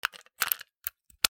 ポータブルラジオ 電池の出し入れ
『カチャ』